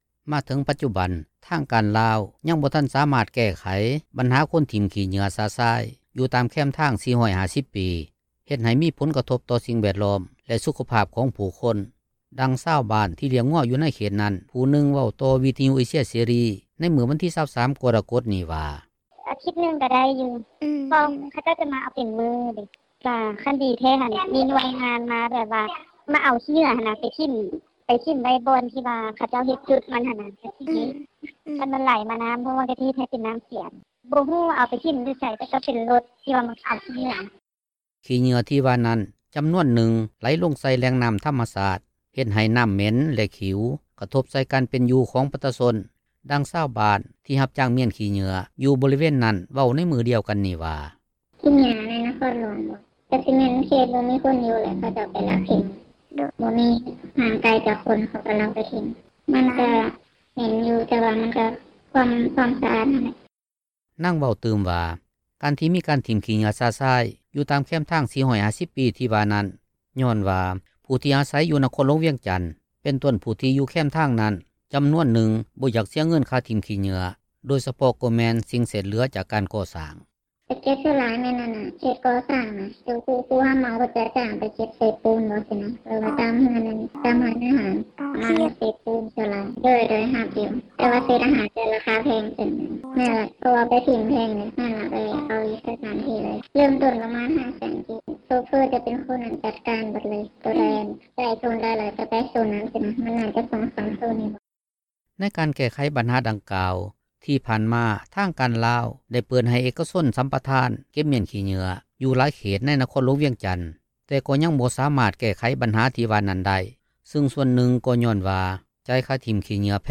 ມາເຖິງປັດຈຸບັນ ທາງການລາວ ຍັງບໍ່ທັນສາມາດແກ້ໄຂບັນຫາຄົນຖິ້ມຂີ້ເຫຍື້ອຊະຊາຍ ຢູ່ຕາມແຄມທາງ 450 ປີ ເຮັດໃຫ້ມີຜົນກະທົບຕໍ່ສິ່ງແວດລ້ອມ ແລະສຸຂະພາບຂອງຜູ້ຄົນ ດັ່ງຊາວບ້ານ ທີ່ລ້ຽງງົວຢູ່ໃນເຂດນັ້ນ ຜູ້ໜຶ່ງເວົ້າຕໍ່ວິທະຍຸເອເຊັຍເສຣີ ໃນມື້ວັນທີ 23 ກໍລະກົດນີ້ວ່າ:
ຂີ້ເຫຍື້ອທີ່ວ່ານັ້ນ ຈໍານວນໜຶ່ງ ໄຫຼລົງໃສ່ແຫຼ່ງນໍ້າທໍາມະຊາດ ເຮັດໃຫ້ນໍ້າເໝັນ ແລະຂິວ ກະທົບໃສ່ການເປັນຢູ່ຂອງປະຊາຊົນ ດັ່ງຊາວບ້ານ ທີ່ຮັບຈ້າງມ້ຽນຂີ້ເຫຍື້ອ ຢູ່ບໍລິເວນນັ້ນ ເວົ້າໃນມື້ດຽວກັນນີ້ວ່າ: